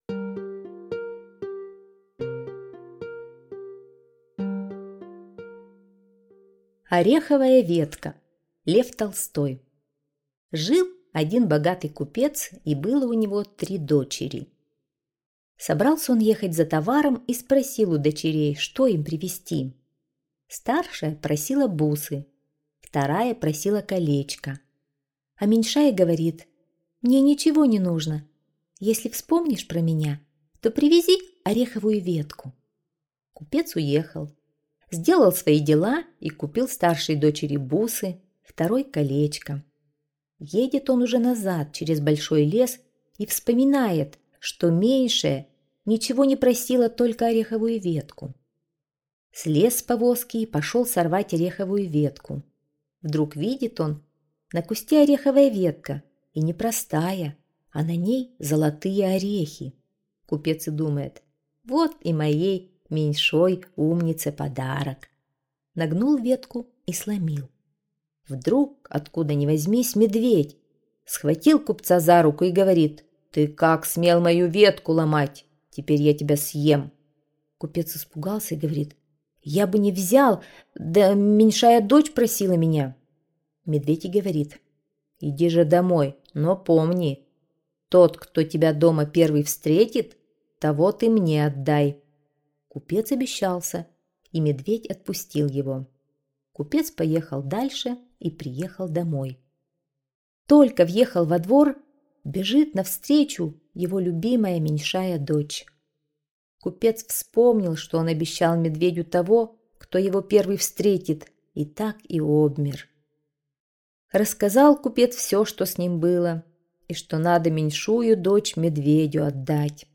Ореховая ветка - аудиосказка Толстого Л.Н. Жил один купец с тремя дочерьми.